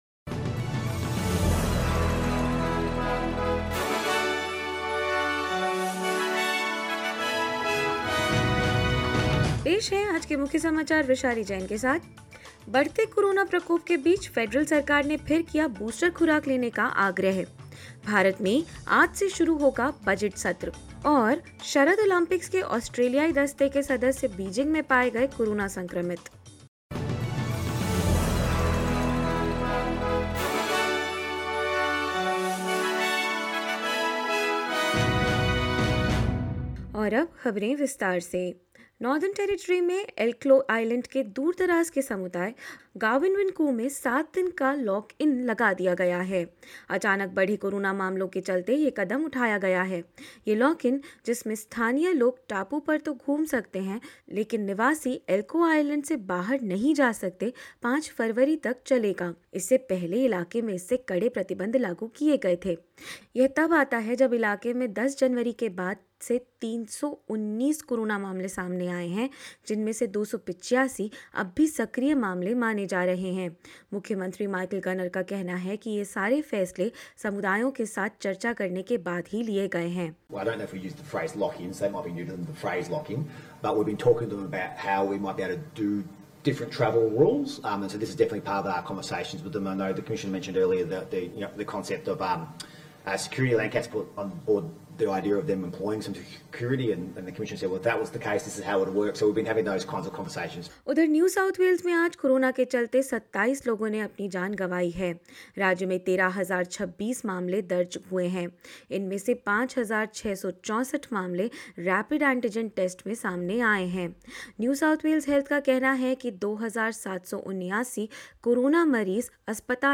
In this latest SBS Hindi bulletin: Remote community of Gawinwin'ku on Elcho Island in NT has entered a seven day lock-in as corona cases rise; India begins its budget session today; Member of Australian Winter Olympics contingent who tested positive on arrival in Beijing tests negative and more news.